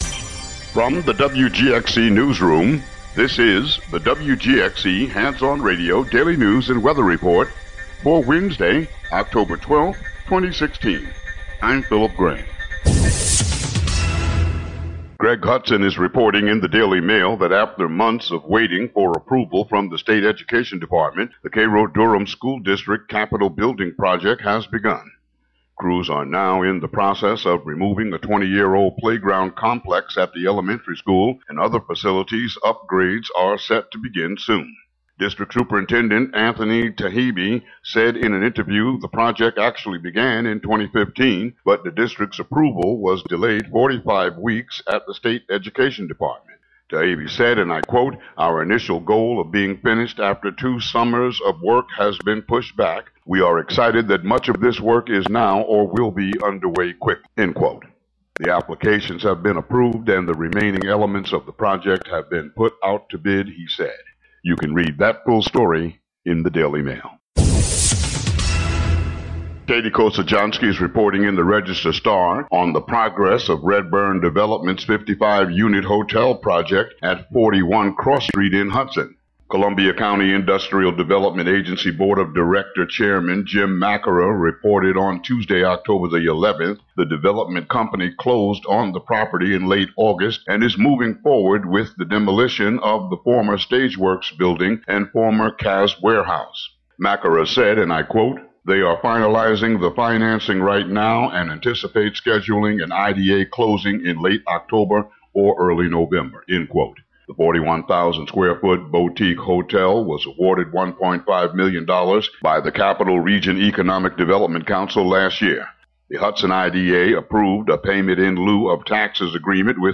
WGXC daily headlines and weather.